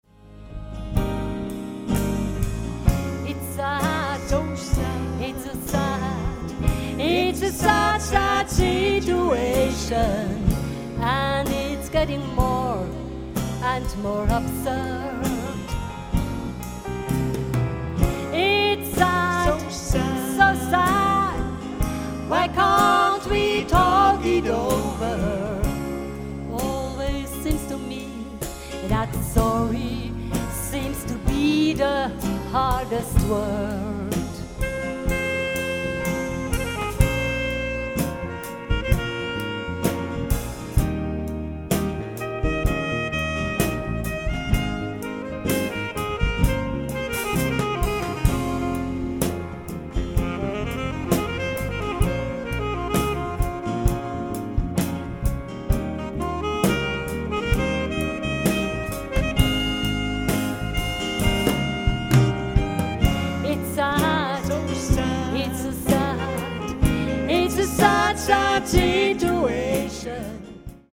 Wohlen, Sternensaal